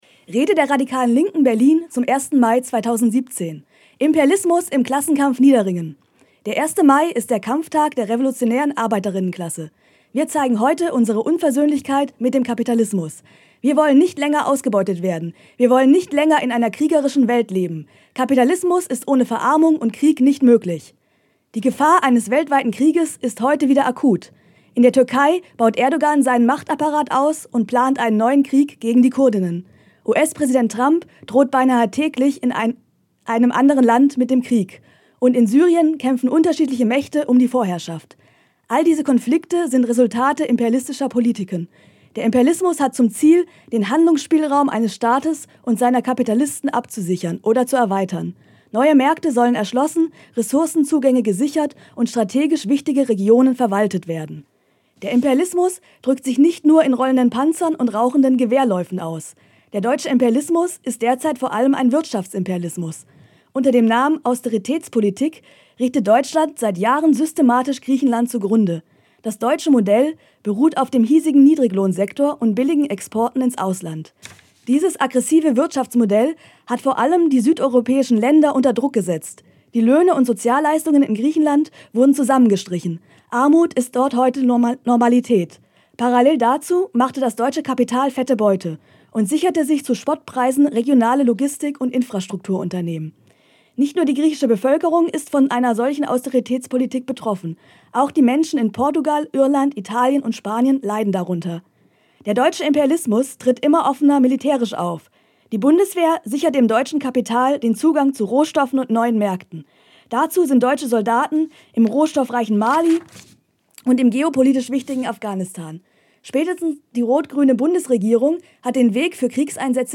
Weil wir aber auch in diesem Jahr nicht auf Redebeiträge verzichten wollen, haben ein paar Gruppen ihre politischen Anliegen bereits im Vorfeld aufgenommen.